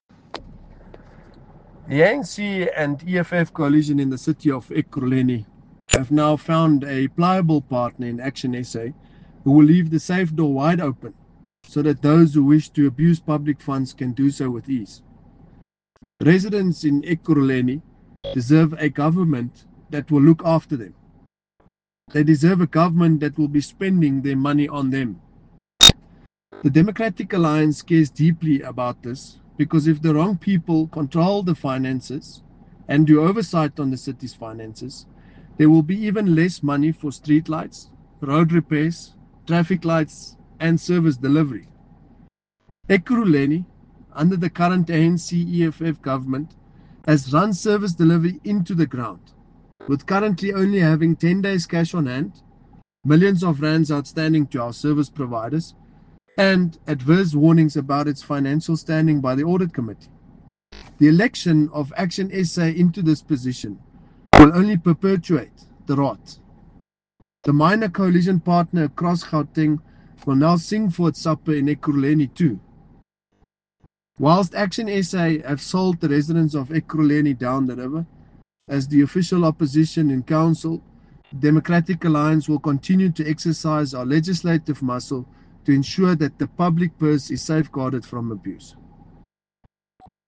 Note to Editors: Please find an English soundbite by Cllr Brandon Pretorius